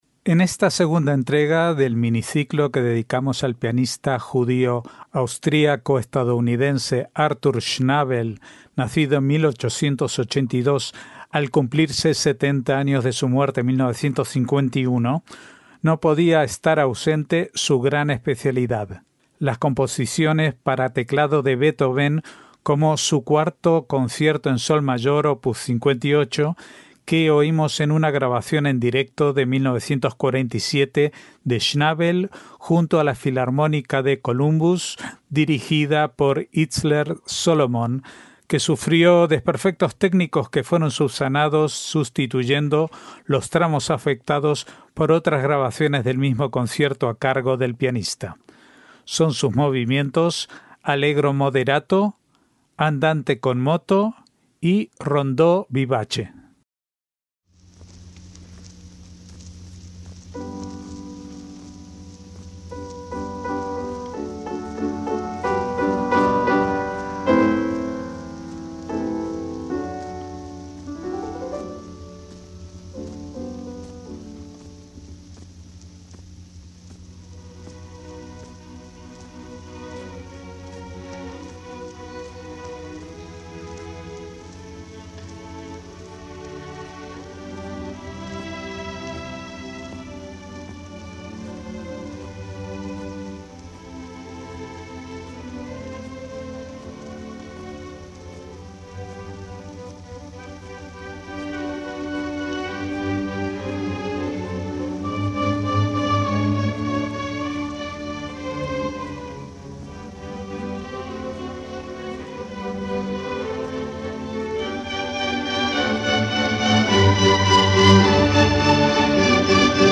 MÚSICA CLÁSICA
una grabación en directo de 1947
Son sus movimientos Allegro moderato, Andante con moto y Rondó (Vivace).